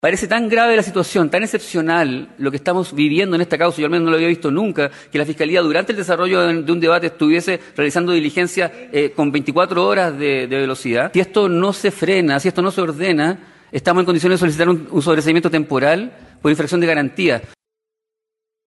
Durante la quinta jornada de formalización del caso “Muñeca Bielorrusa”, la Fiscalía y las defensas protagonizaron un extenso intercambio marcado por acusaciones de vulneraciones al debido proceso y la presentación de nuevos antecedentes que, según el Ministerio Público, confirman los delitos de cohecho y lavado de activos.